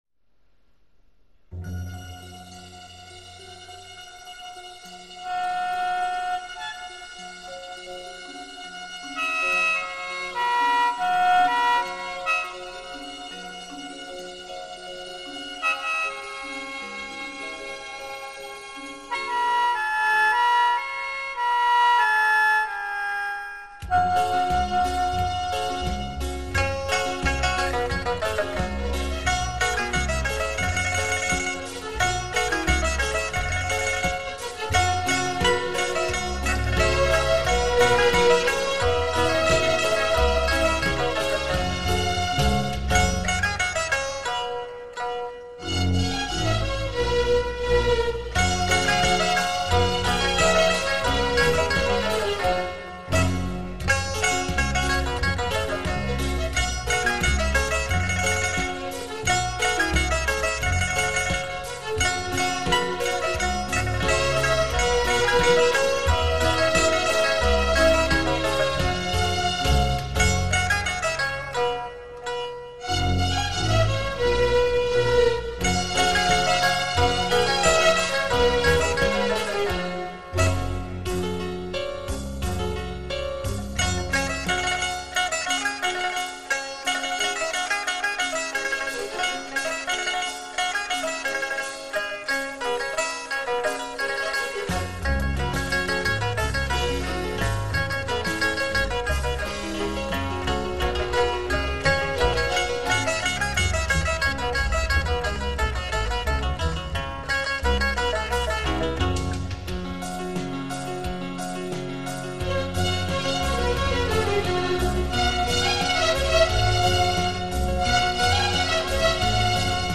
国乐/民族
本辑是青海民歌改编的轻音乐。中国民族乐器为主奏，辅以西洋管弦乐器及电声乐器。
最新数码系统录制，堪称民乐天碟。